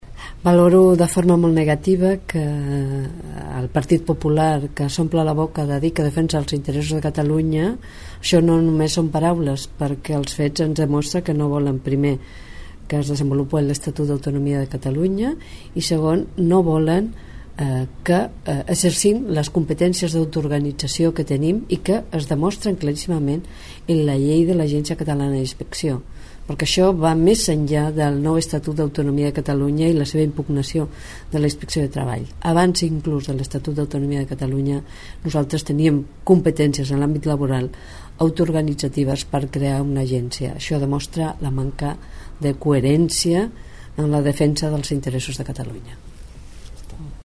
La consellera de Treball, Mar Serna, valora la decisió del PP d'elevar el projecte de llei de l'ACIT al Consell de Garanties Estatutàries.